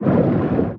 Sfx_creature_pinnacarid_swim_fast_01.ogg